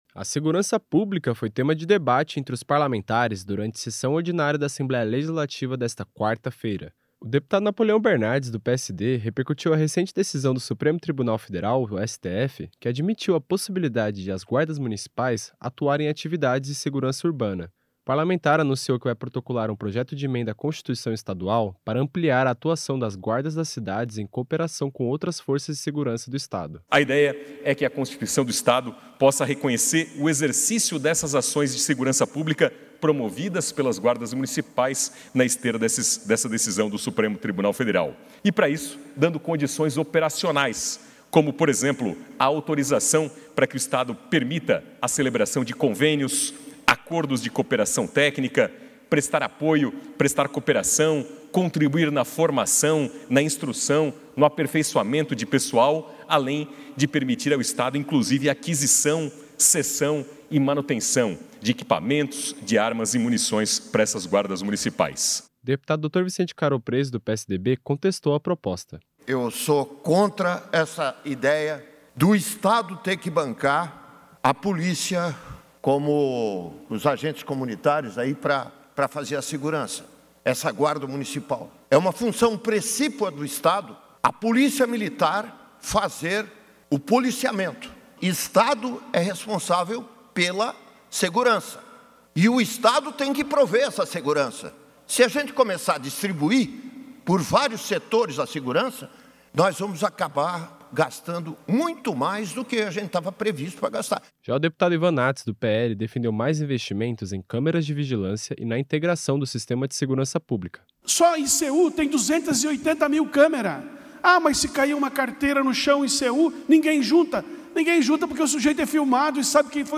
Entrevistas com:
- deputado Napoleão Bernardes (PSD);
- deputado Dr Vicente Caropreso (PSDB);
- deputado Ivan Naatz (PL).